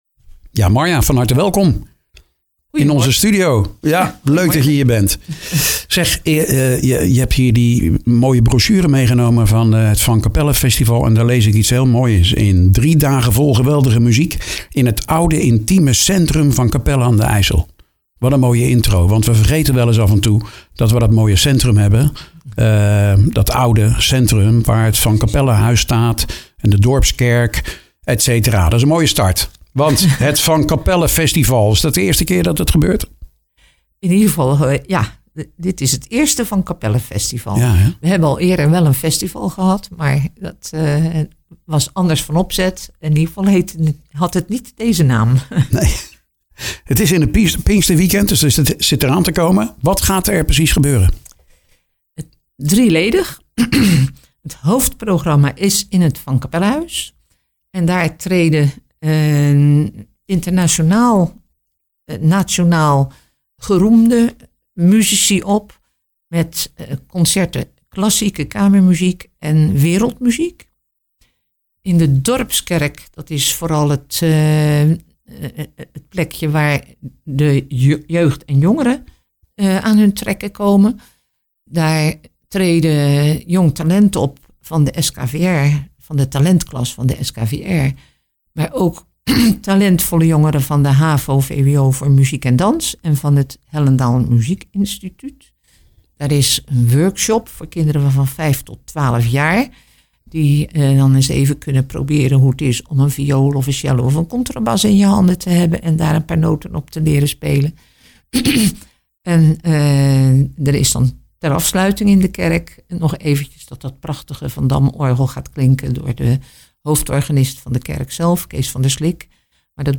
praatte in de studio met